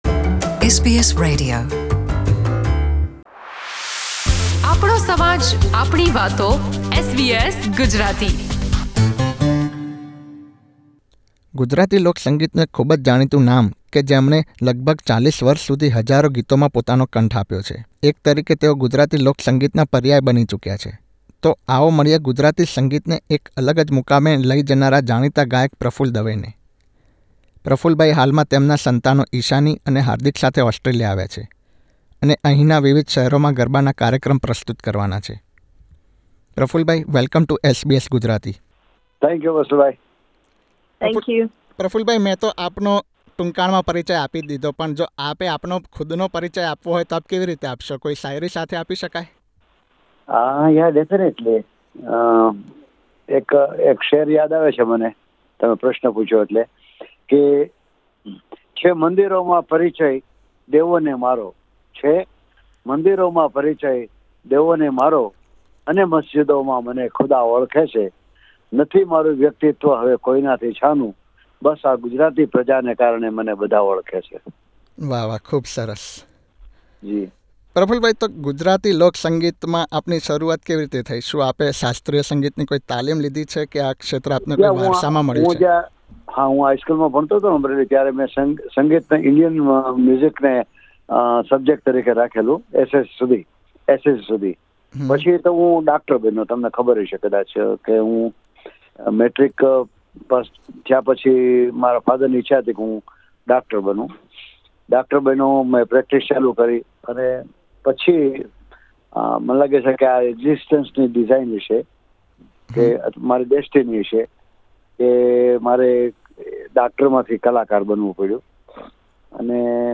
કારકિર્દીના શરૂઆતના વર્ષોમાં ડોક્ટર તરીકે સેવા આપનારા જાણીતા ગુજરાતી લોકસંગીતના લોકપ્રિય ગાયક પ્રફુલ દવેએ ચાર દાયકાની તેમની સંગીત યાત્રામાં હજારો ગીતોમાં પોતાનો કંઠ આપ્યો છે. SBS Gujarati સાથેની વિશેષ વાતચીત દરમિયાન તેમણે ગુજરાતી લોકસંગીત, પરિવારનો સહયોગ અને ડોક્ટર તરીકેની કારકિર્દી છોડીને સંગીત ક્ષેત્રની સફર વિશે વાત કરી હતી.